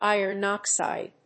アクセントíron óxide